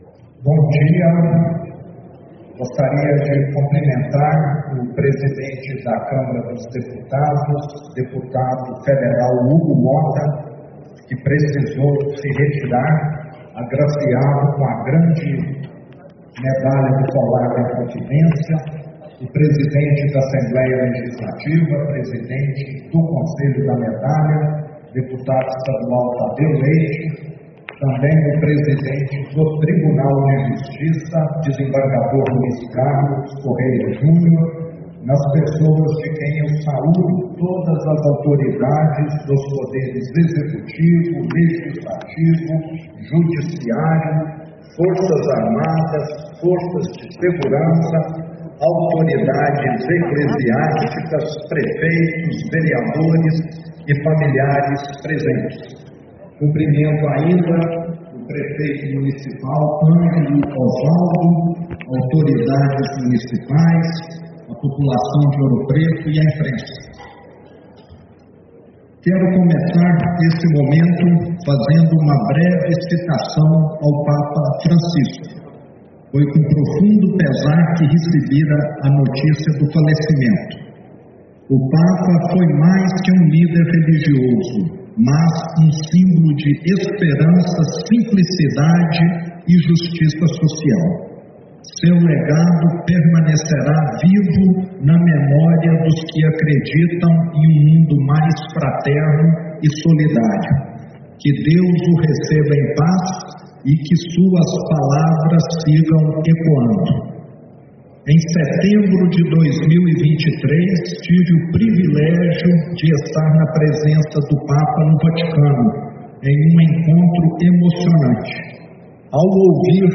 Pronunciamento do governador Romeu Zema na cerimônia de entrega da Medalha da Inconfidência 2025